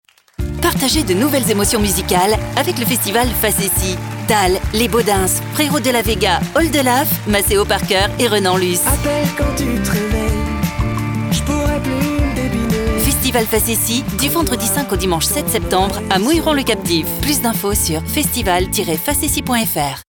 Ma voix peut être chaleureuse, explicative, douce, dynamique, sensuelle ou enjouée.
Sprechprobe: Werbung (Muttersprache):
I have an explaning, soft, dynamic, warm or sensual voice.